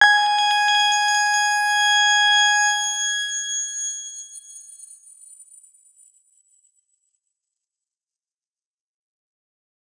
X_Grain-G#5-ff.wav